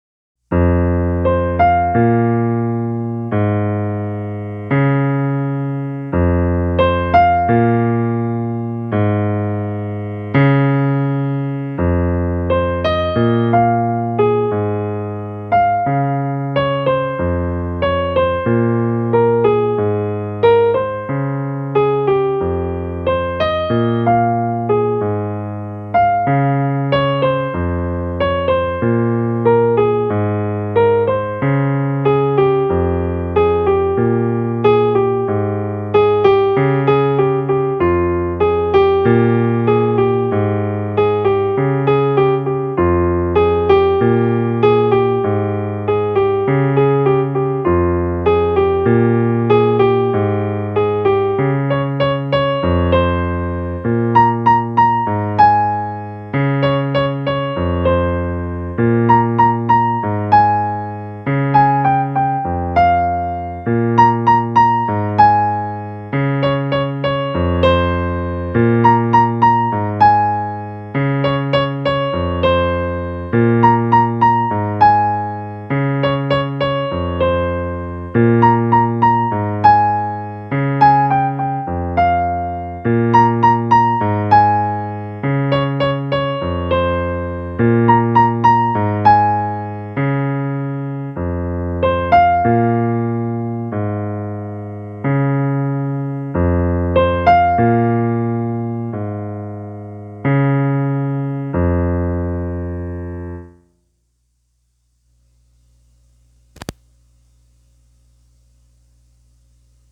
Poetry